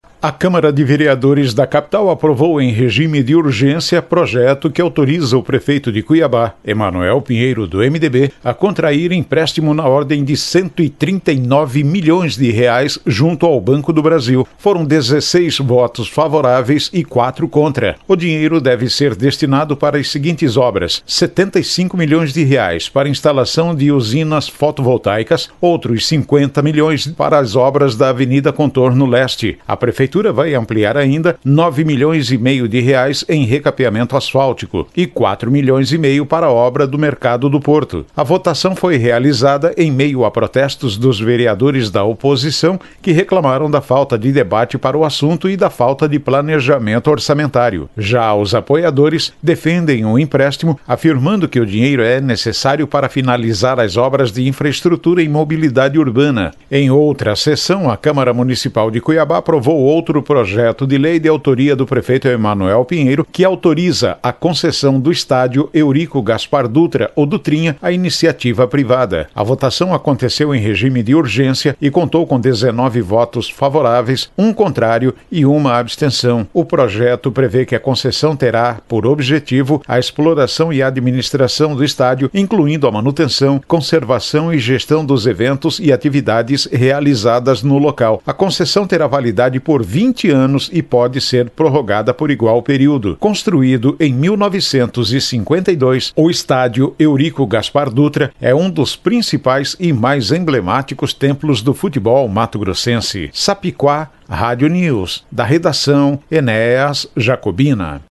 Boletins de MT 18 jul, 2024